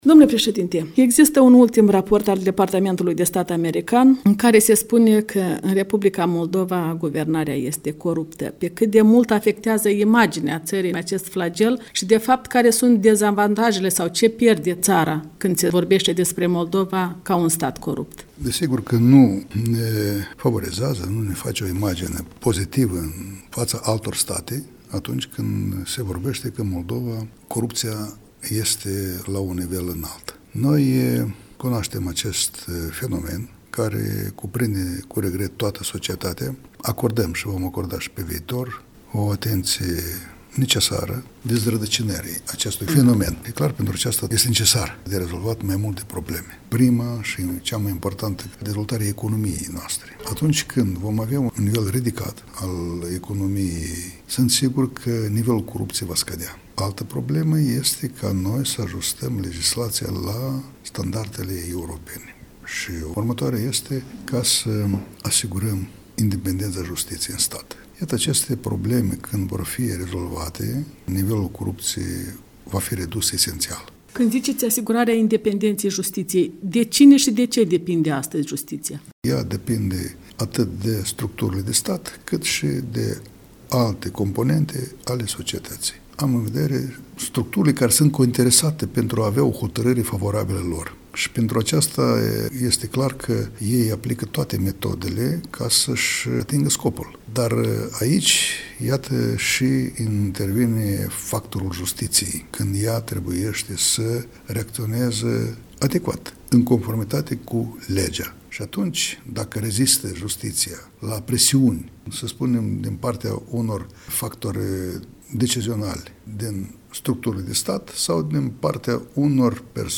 Un interviu cu președintele Nicolae Timofti în exclusivitate pentru Europa Liberă